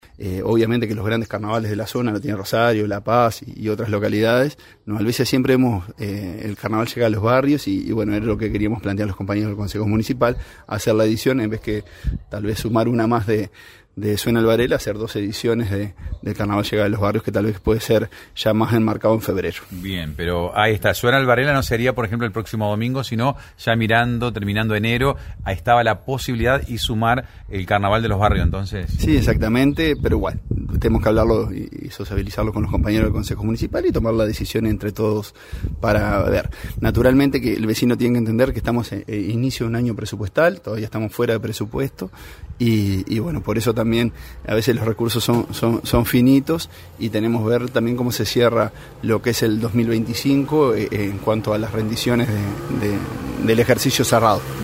El alcalde, Marcelo Alonso, brindó detalles sobre los avances en la planificación de las festividades de Carnaval para este año.